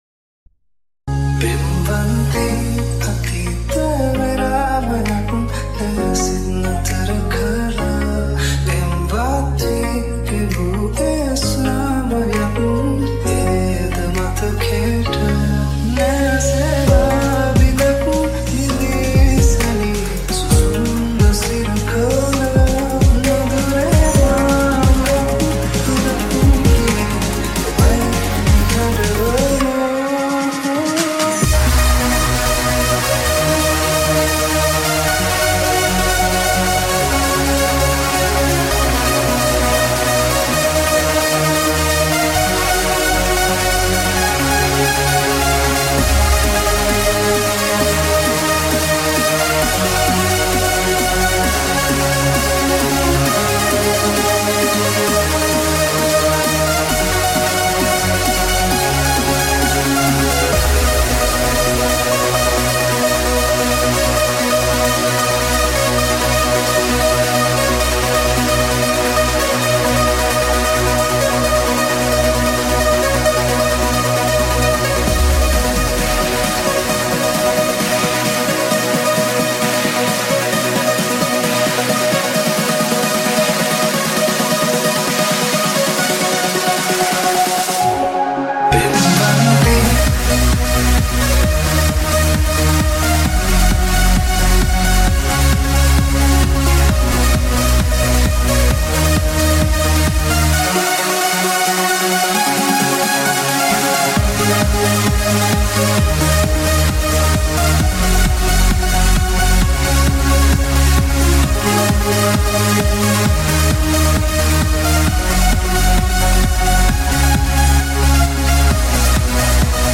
Organic House Remix